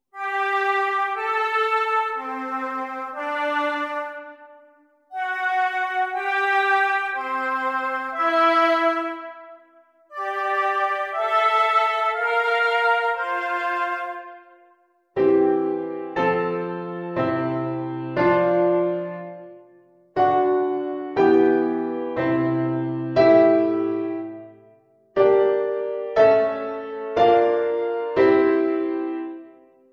verboden parallellen